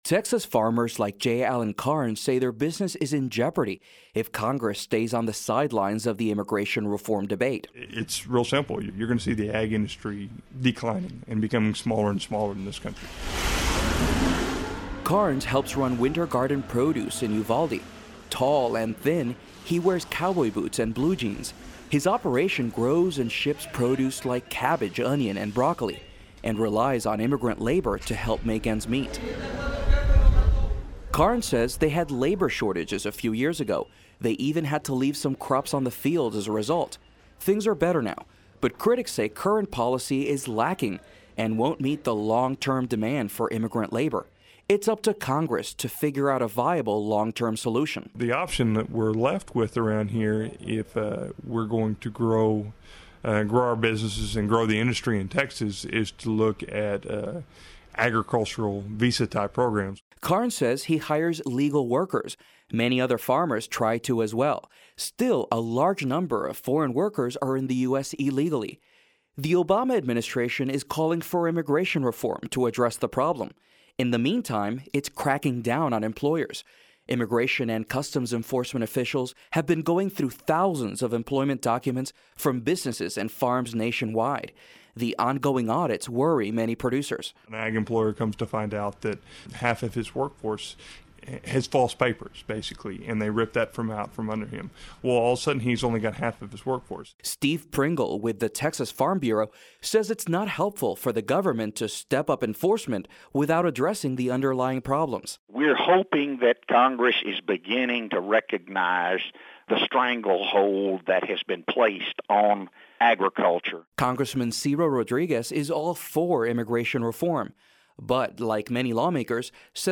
Radio version for KSTX-FM in San Antonio, Texas